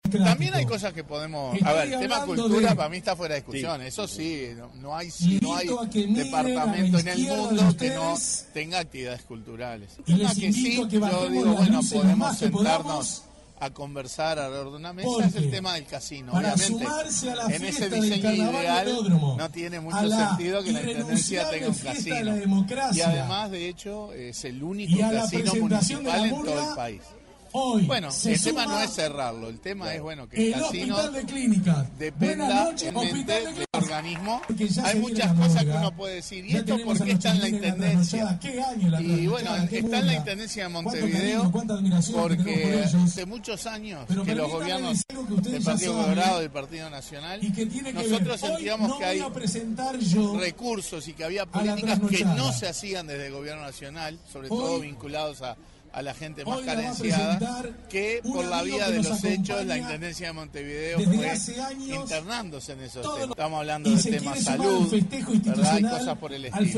“Podemos sentarnos a conversar” al respecto, agregó, en diálogo mantenido días atrás con Informativo Uruguay, en el Velódromo Municipal.